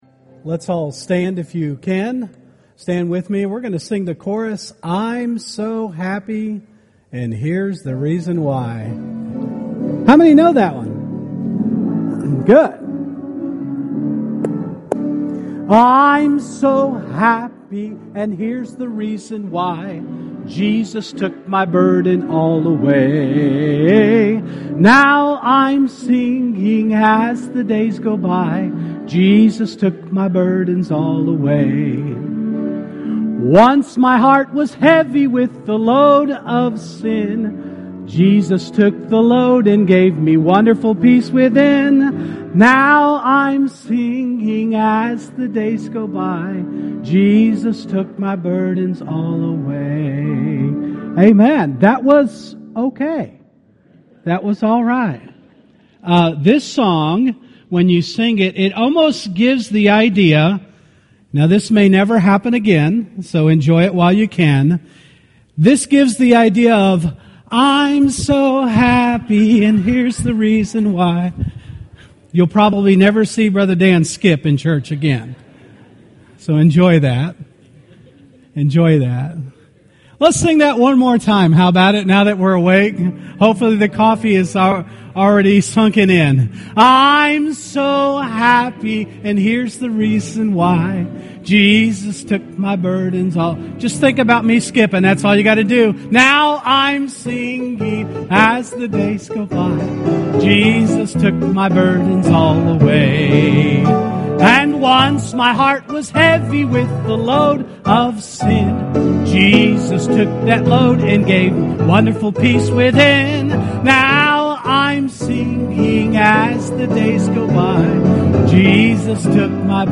Sunday School Lesson | Buffalo Ridge Baptist Church